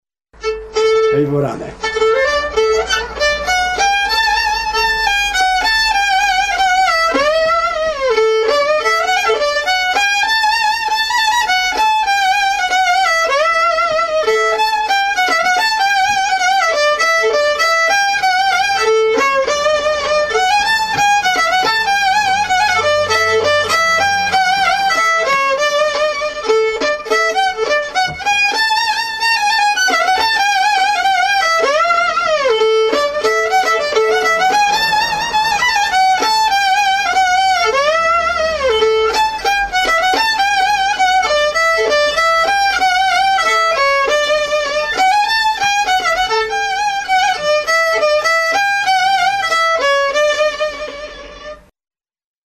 Gra przede wszystkim na skrzypcach, ale również na „harmonii” (akordeonie) i organach.